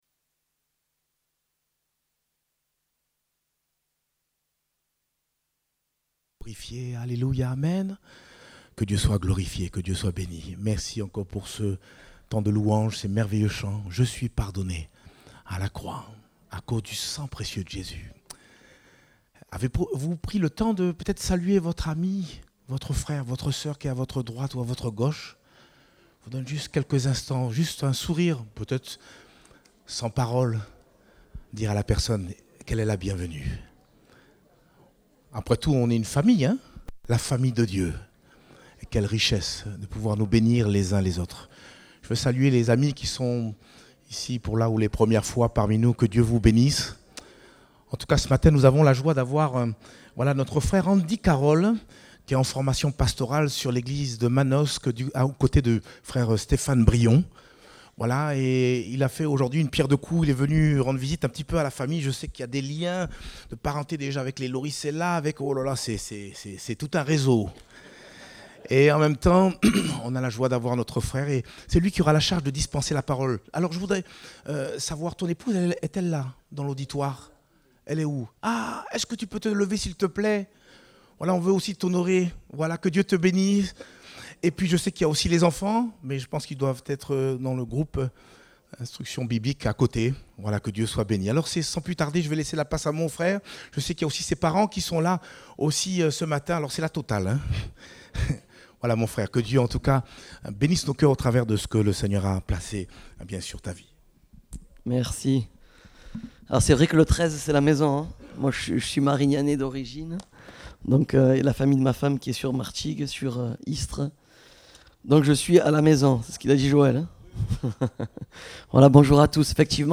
Date : 11 juin 2023 (Culte Dominical)